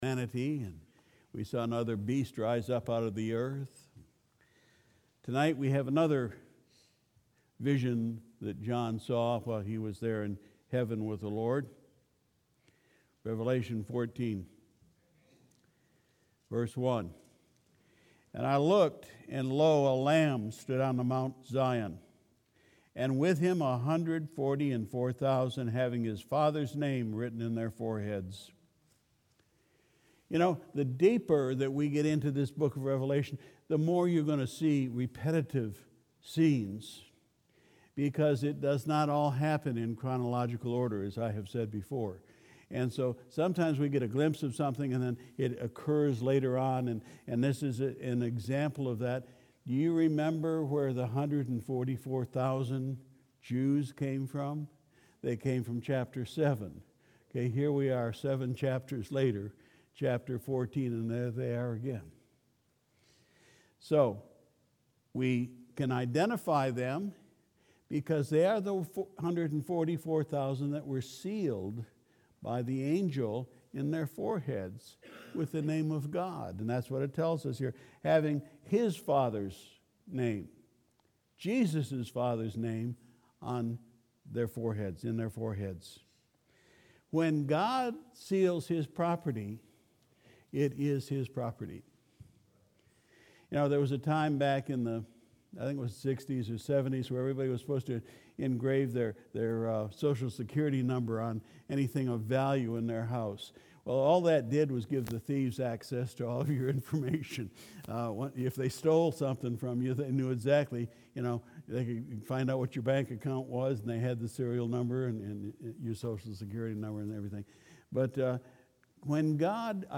February 9, 2020 Sunday Evening Service We continued our study in the Book of Revelation